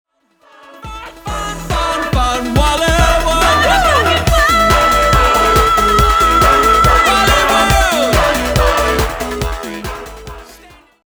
In addition to singing with the choir
step-out solo